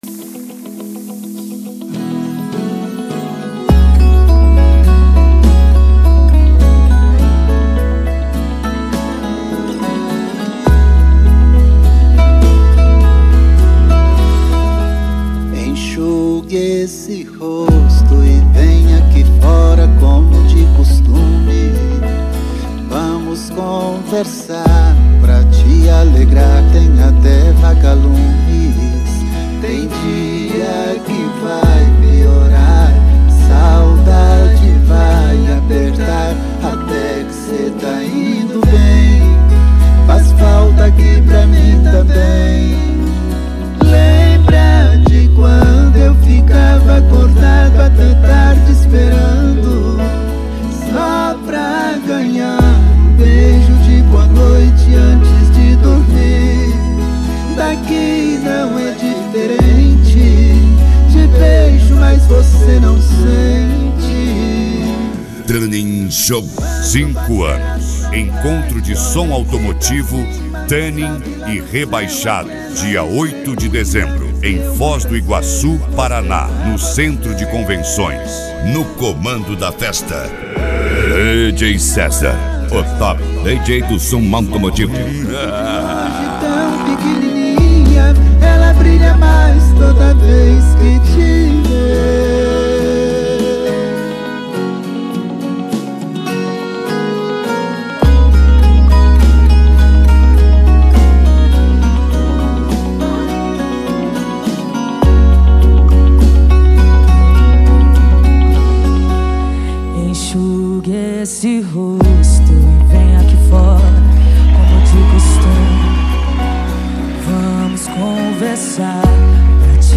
Mega Funk
Racha De Som